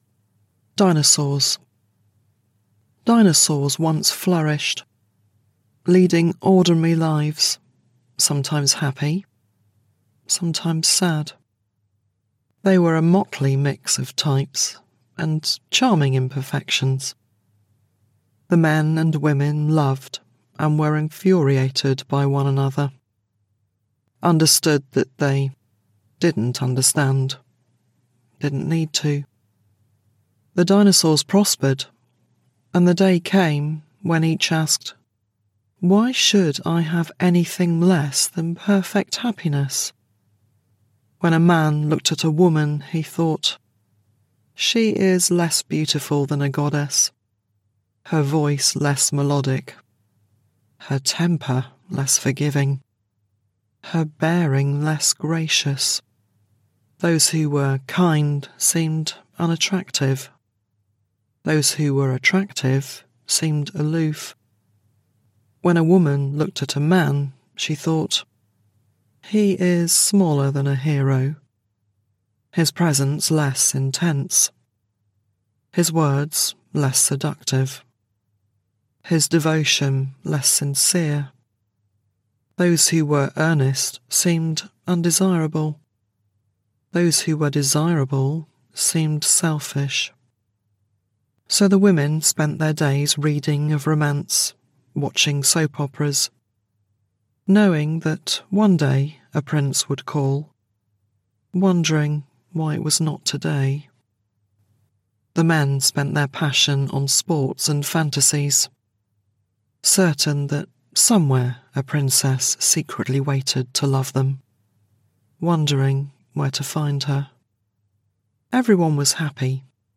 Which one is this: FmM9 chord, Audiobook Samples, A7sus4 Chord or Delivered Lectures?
Audiobook Samples